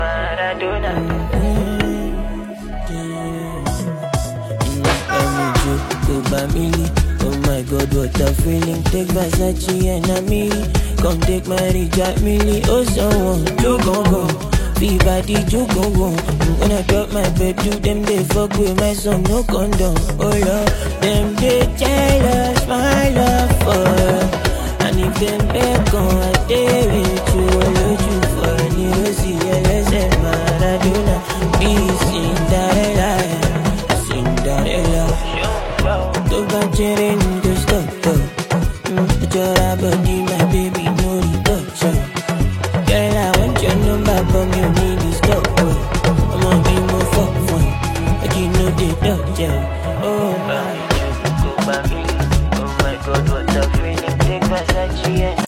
known for his smooth vocal delivery and genre-bending sound